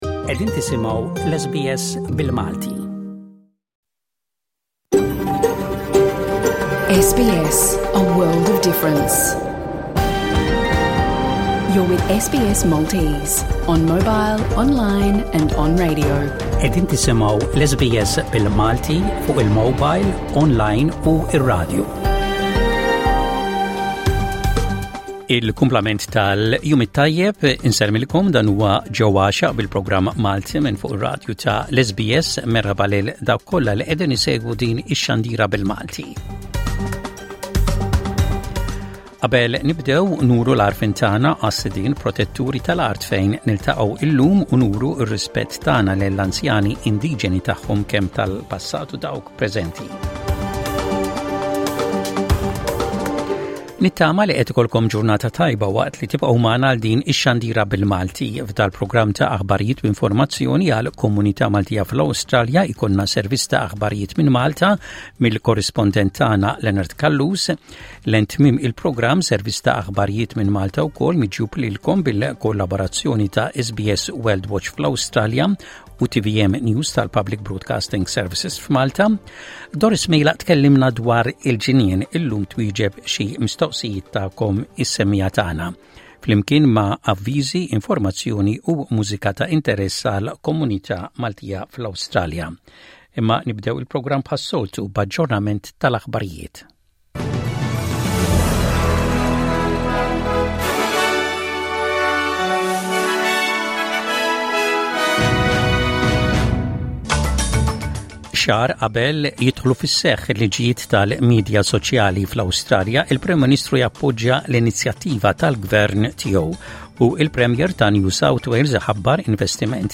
L-aħbarijiet mill-Awstralja u l-kumplament tad-dinja